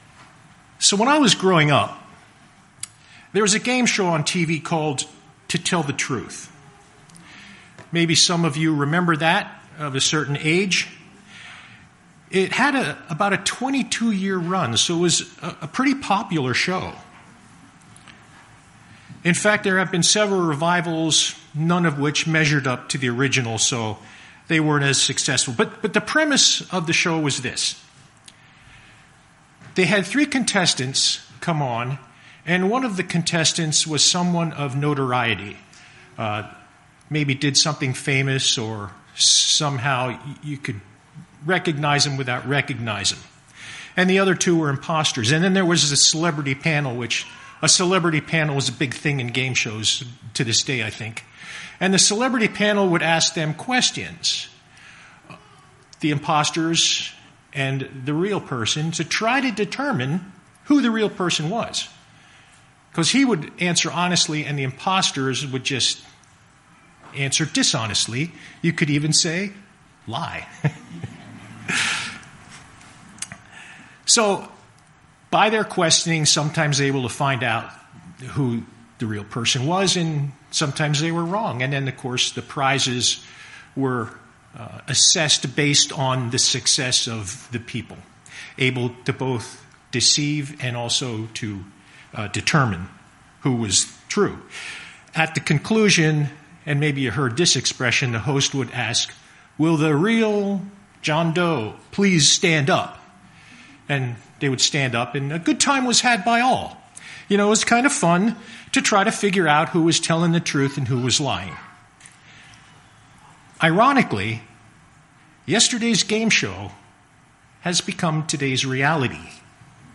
Given in Lehigh Valley, PA